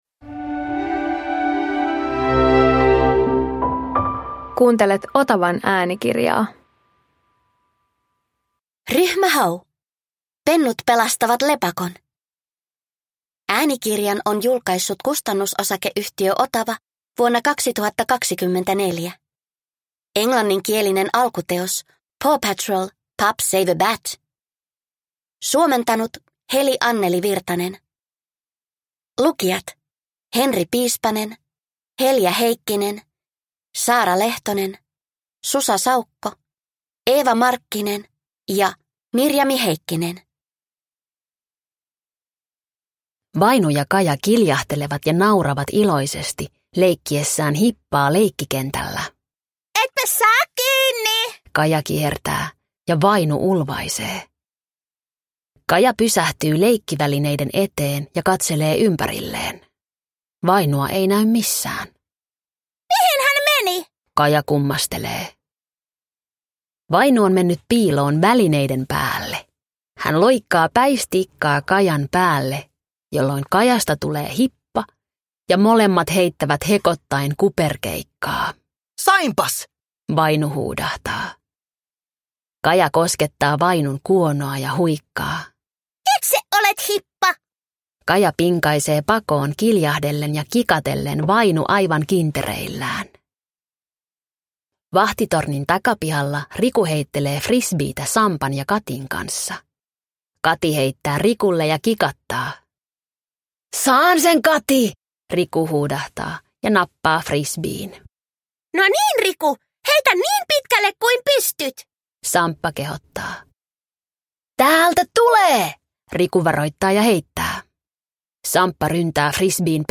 Ryhmä Hau - Pennut pelastavat lepakon – Ljudbok